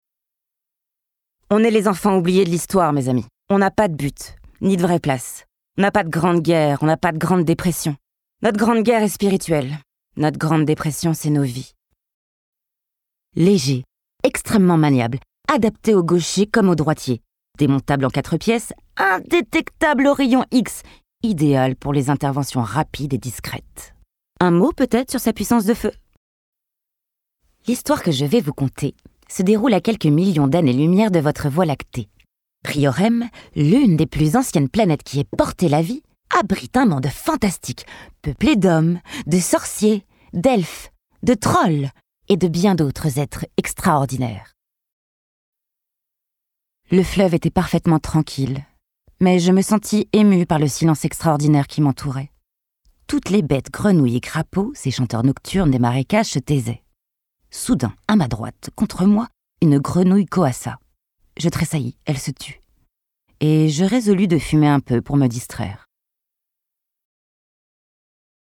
Bandes-son
Bande démo - dessins animés